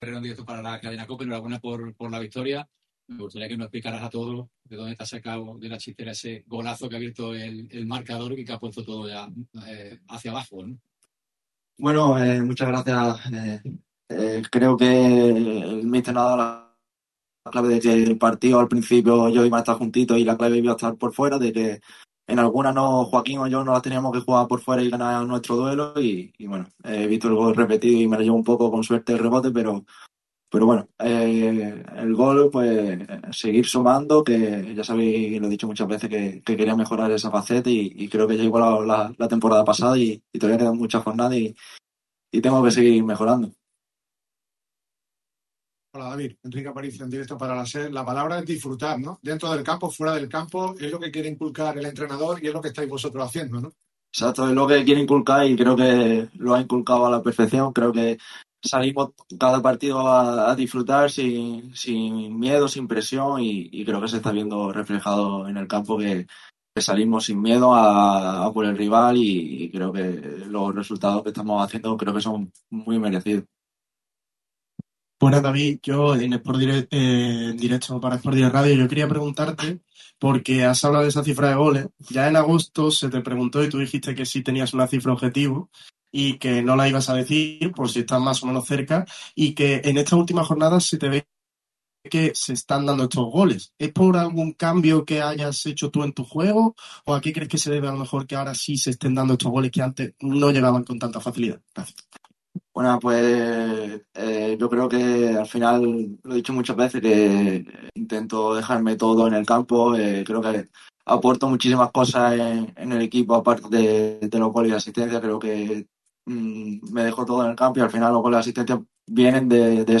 Rueda de prensa completa